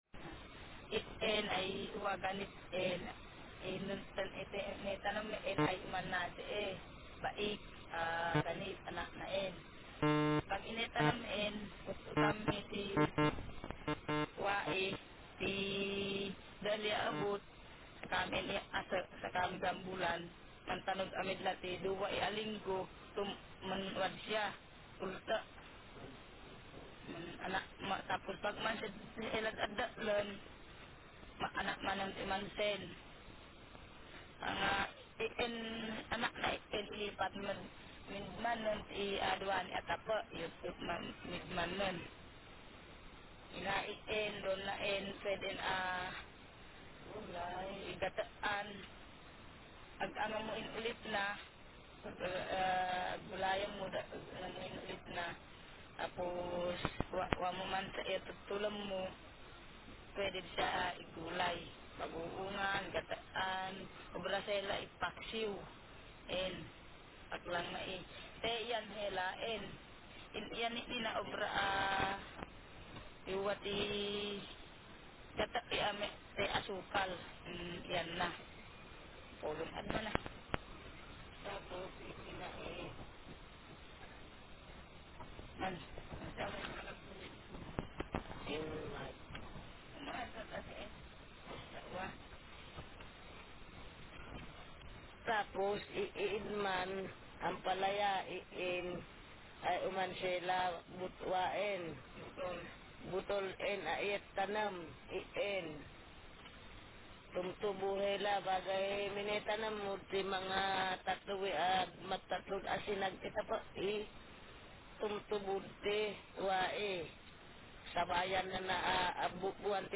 Speaker sex f Text genre procedural